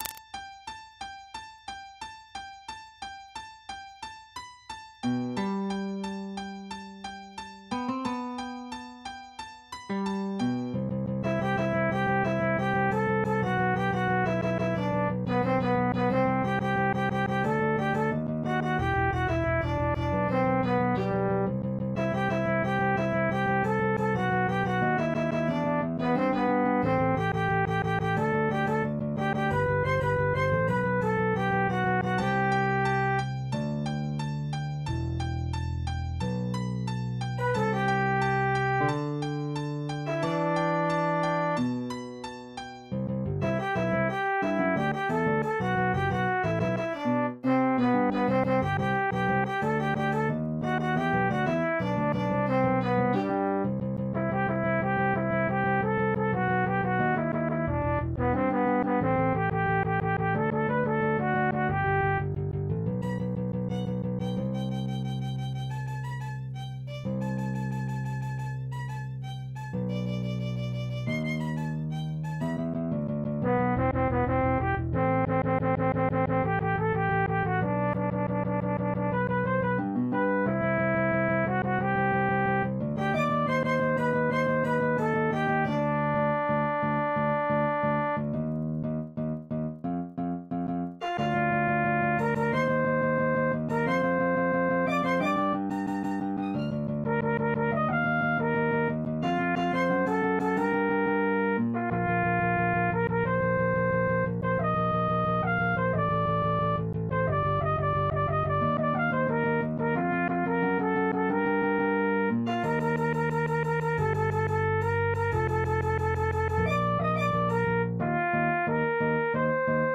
MIDI 22.73 KB MP3 (Converted) 4.83 MB MIDI-XML Sheet Music